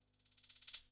TI124PERC1-L.wav